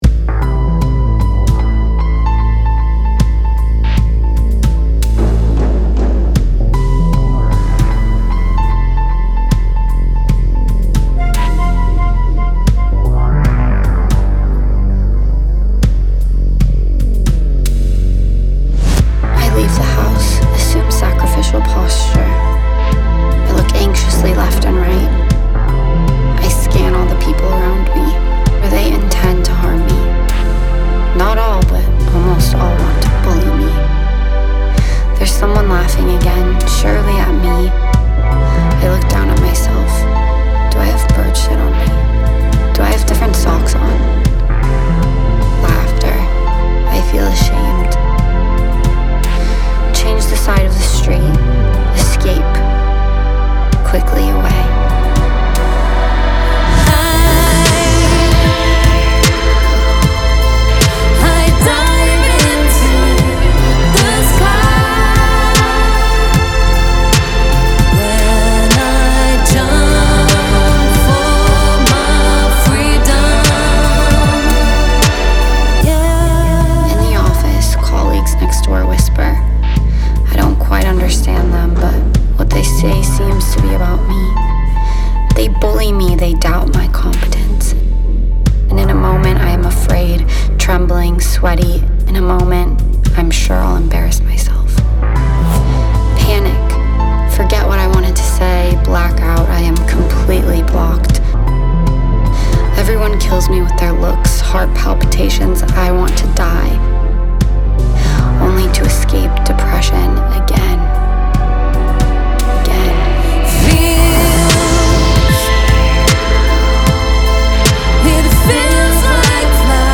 Vocal performance anonymous